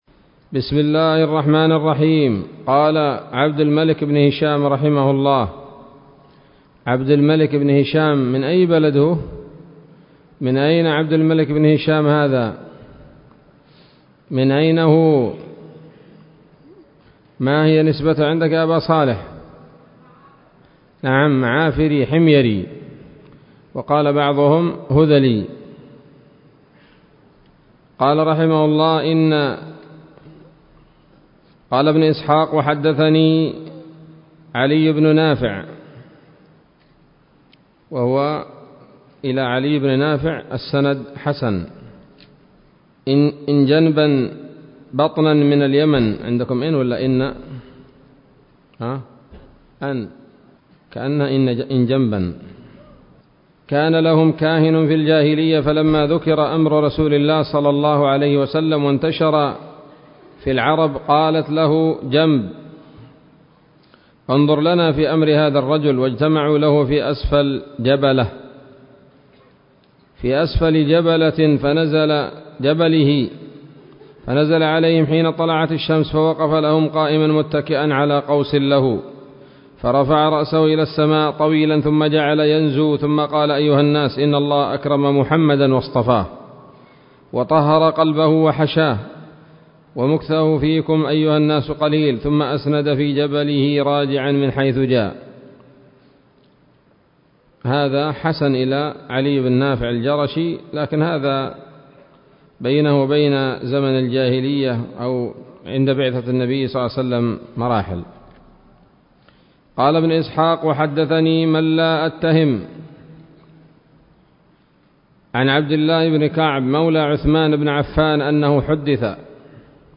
الدرس الثامن عشر من التعليق على كتاب السيرة النبوية لابن هشام